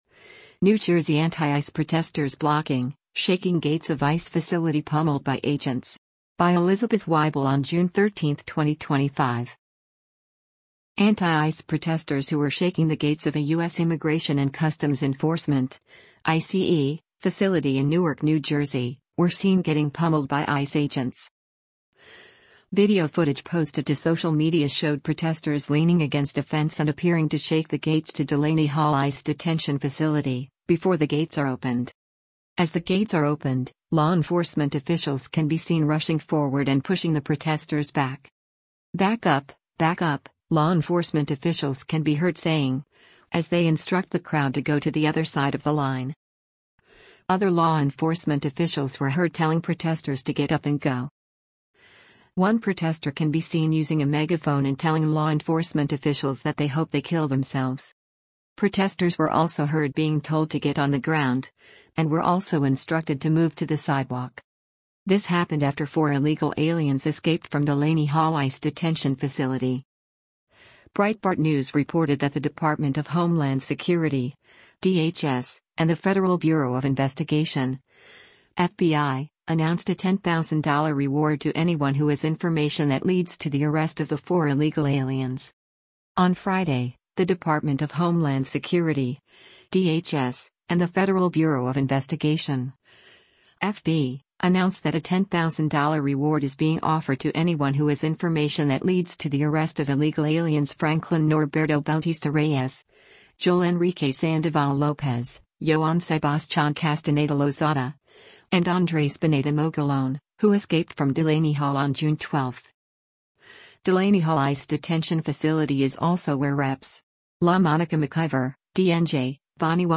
“Back up, back up,” law enforcement officials can be heard saying, as they instruct the crowd to go to the “other side of the line.”
Other law enforcement officials were heard telling protesters to “get up and go.”
One protester can be seen using a megaphone and telling law enforcement officials that they hope they “kill” themselves.